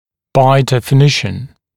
[baɪ ˌdefɪ’nɪʃn][бай ˌдэфи’нишн]по определению